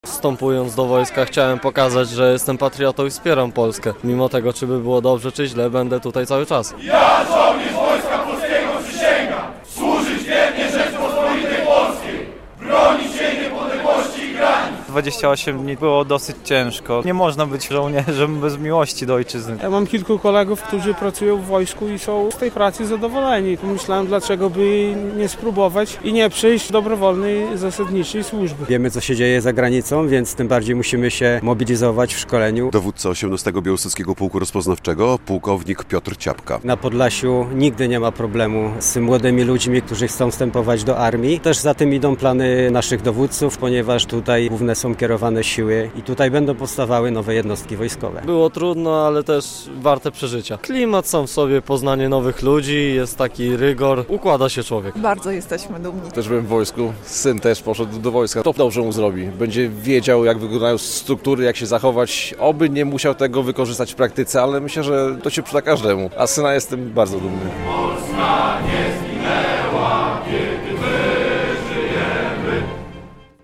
Uroczysta przysięga wojskowa w Białymstoku